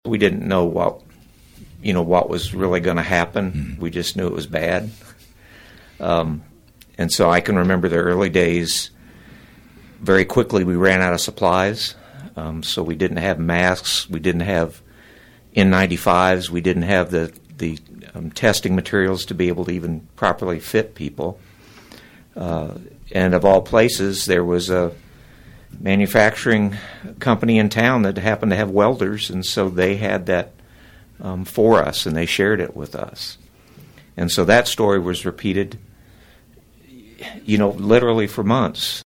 Through all the changes brought about by the COVID-19 pandemic, there were two themes that underpinned Friday’s visit by Newman Regional Health administrators to KVOE’s Talk of Emporia: dedication and gratitude.